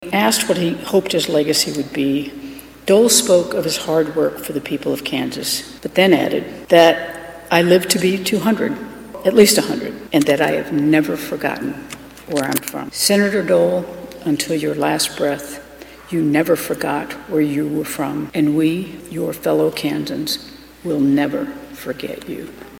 Governor Laura Kelly started her speech thanking current and former Kansas senators, saying they are following in the foot prints of Dole.